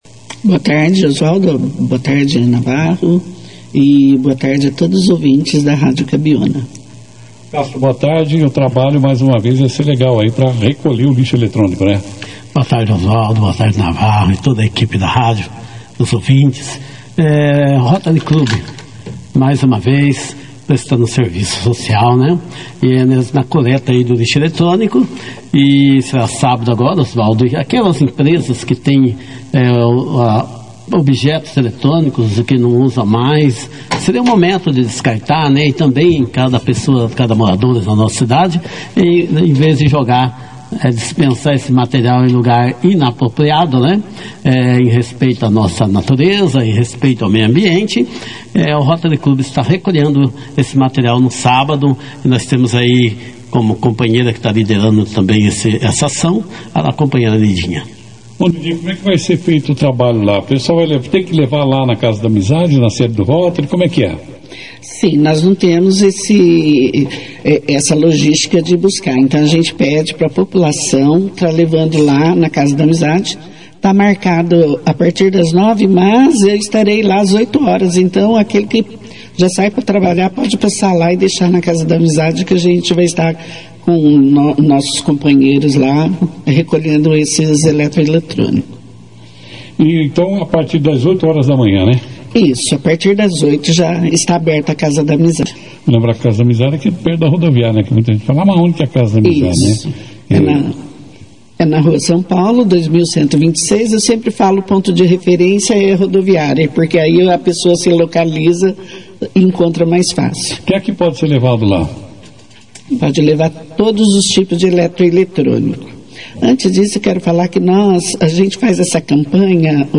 Durante a entrevista, destacaram a relevância da ação, que permitirá à comunidade descartar de maneira adequada diversos equipamentos eletrônicos, tais como telefones, impressoras, monitores, notebooks, CPUs, placas, fax, câmeras, rádios, televisores, entre outros.